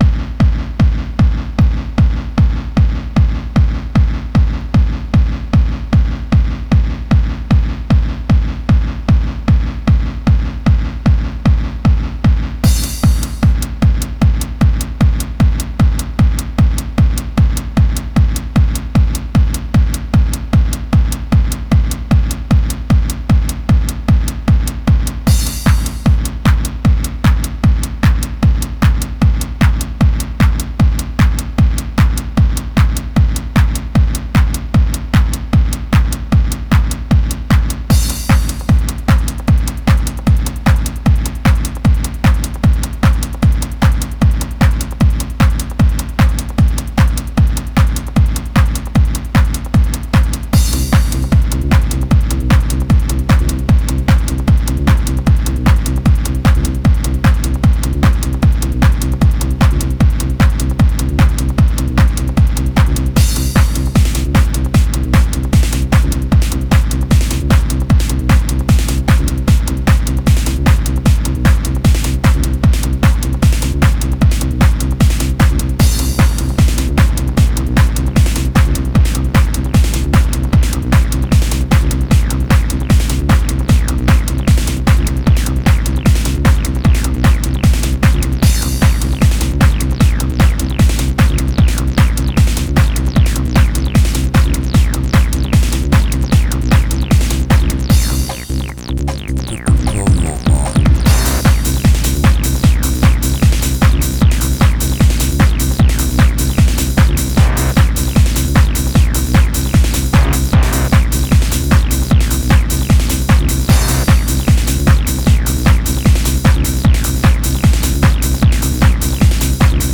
Acid Techno
acid techno roller
Recorded at Kinetec, London.